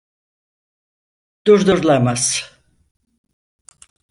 Pronunciato come (IPA)
/duɾ.dʊ.ɾu.ɫɑ.maz/